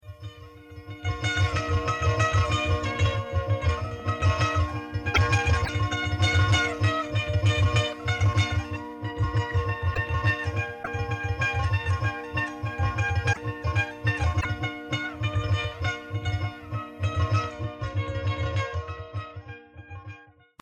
épinette du Nord
deux airs Extrait d’une K7 de collectage : Musiciens et chanteurs Flandres, Artois, Wallonie, association Traces - 1986
joue sur une Coupleux achetée à Lille en 1907.